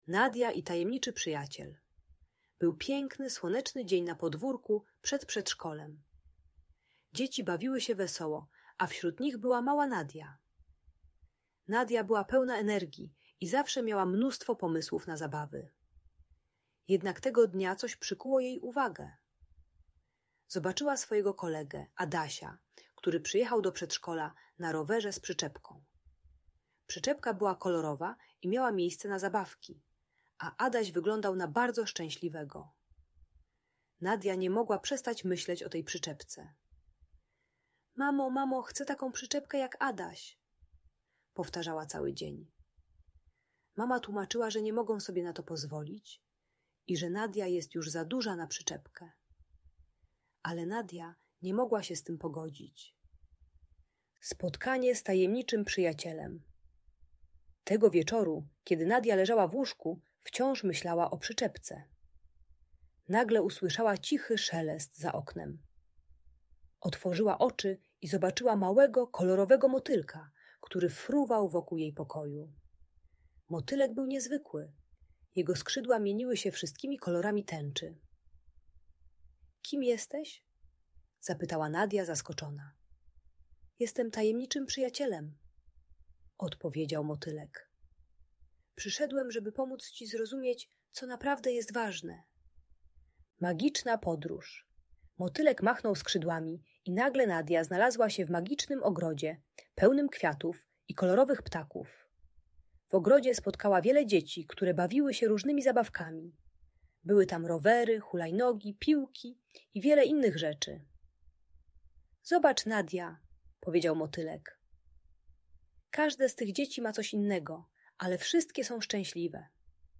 Nadia i Tajemniczy Przyjaciel - Niepokojące zachowania | Audiobajka